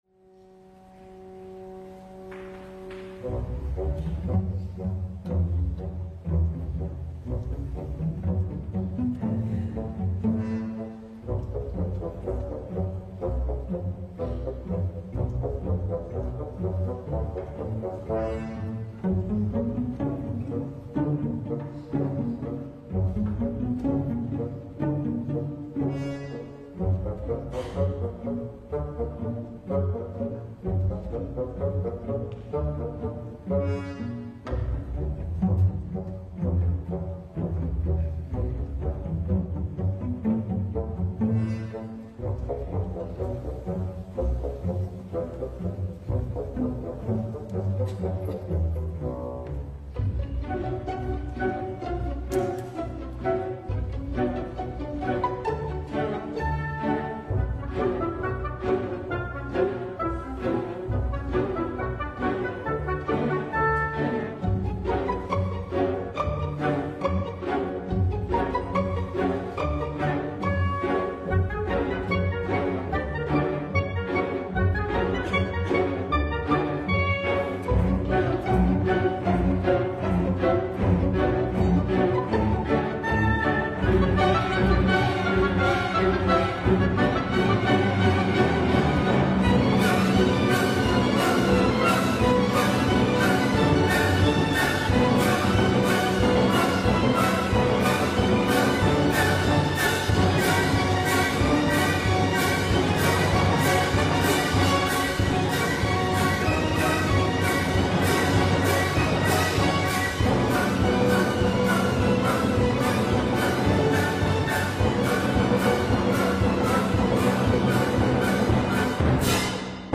Se trata de la Obertura “Las Bodas de Fígaro” del genial compositor de Salzburgo, Mozart; Extractos de las Suites No.1 y  No. 2 de Peer Gynt del autor noruego Grieg y la Sinfonía No.8 “Inconclusa” del austriaco Schubert, todas obras de repertorio para gran orquesta como la OSY.